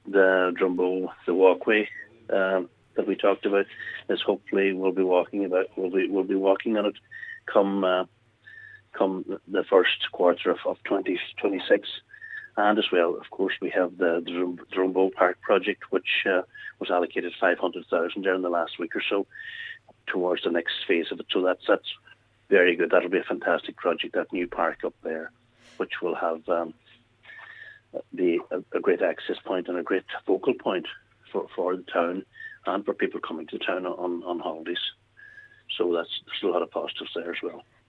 The site has been closed for 13 years, and Cllr Harley says it is something he has personally been lobbying for: